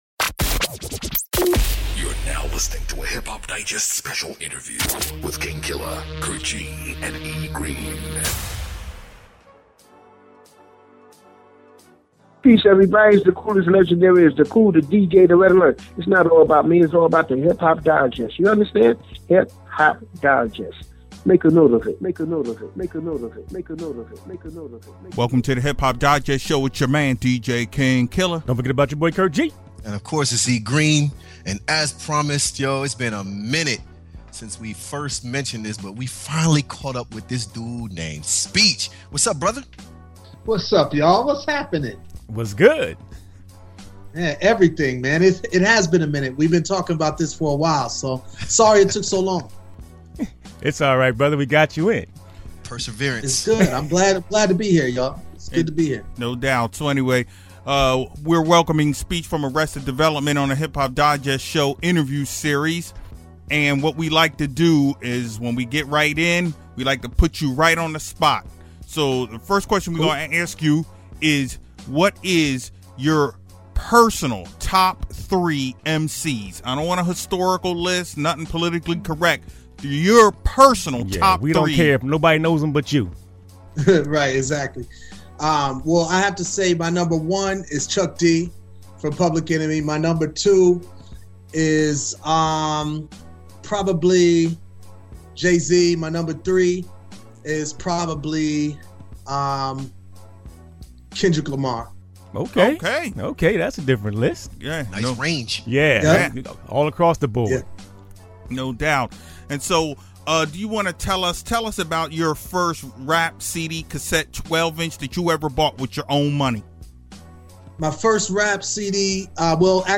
Hip-Hop Digest Show 426 – Interview Series w/Speech from Arrested Development
We had Speech from Arrested Development on with us chopping up all kinds of topics.